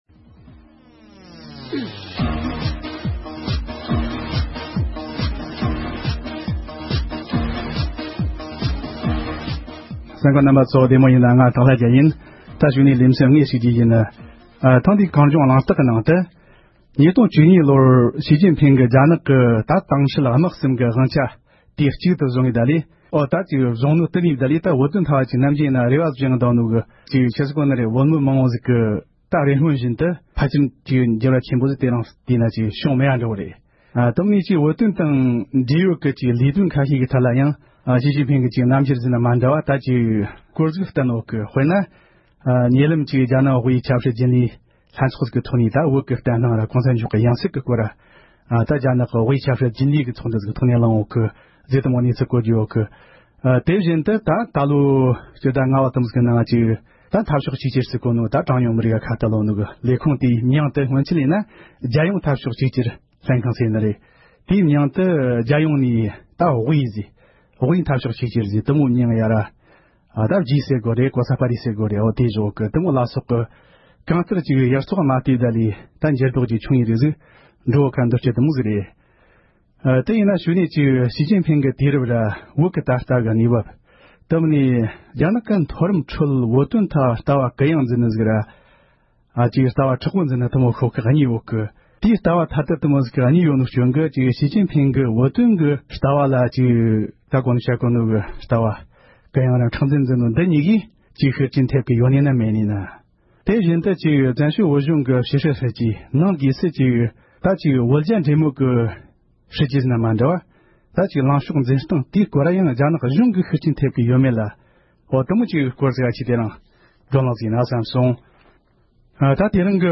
འབྲེལ་ཡོད་མི་སྣ་ཁག་དང་ལྷན་དུ་གླེང་མོལ་ཞུས་པར་གསན་རོགས་ཞུ༎